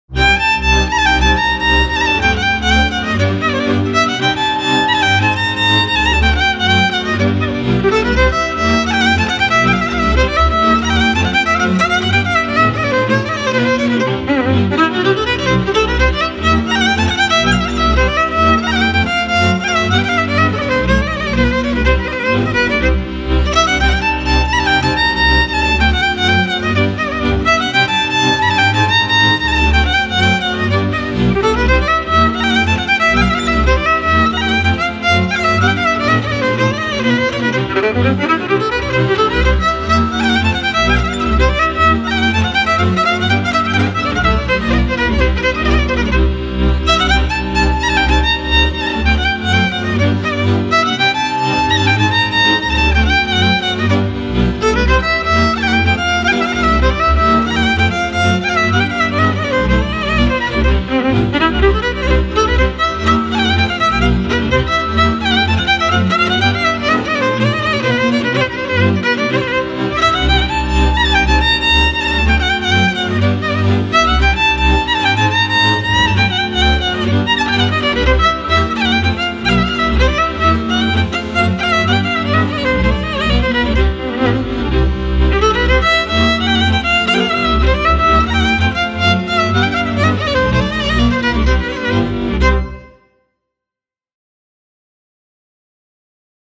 - realizează o bună omogenitate timbrală datorată celor trei instrumente din categoria “cu  coarde şi arcuş”
contrabasul  – frecvenţele grave
braciul cu trei coarde – frecvenţele medii
vioara – frecvenţele înalte